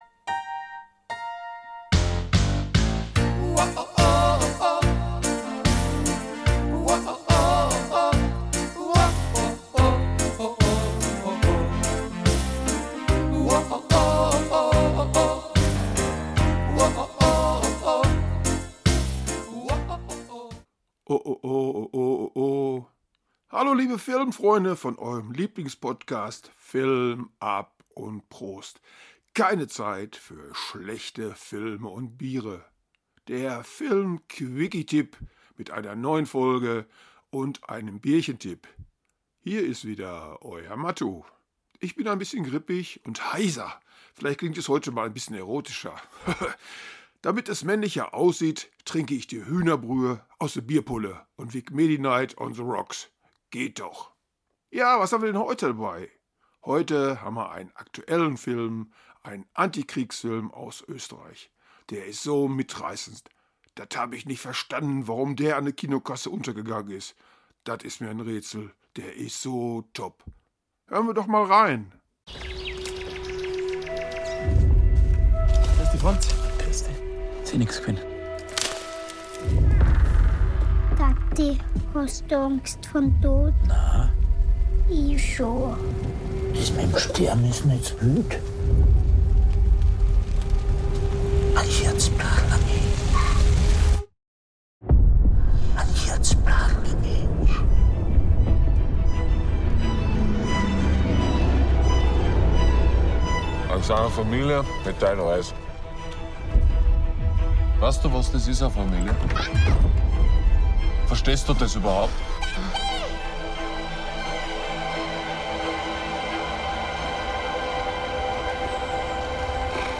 Der Filmquickie - Tipp mit Ruhrpottcharme und lecker Bierchen